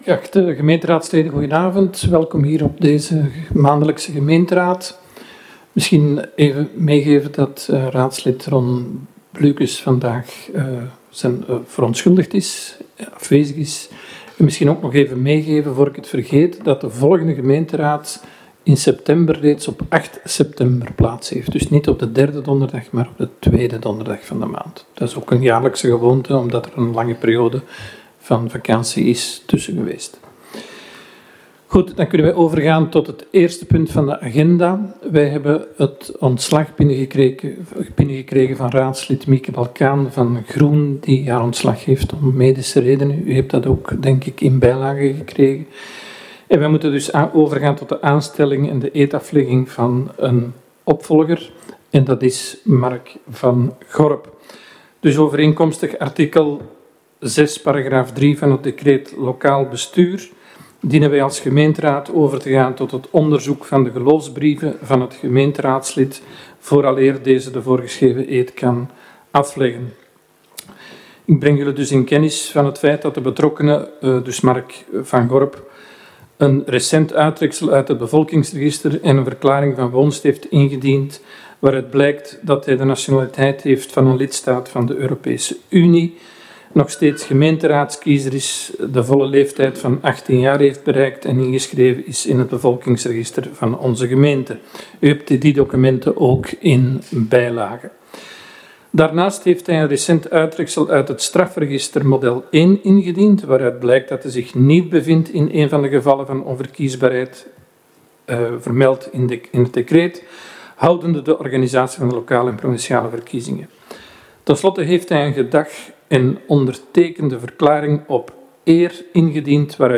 Gemeentehuis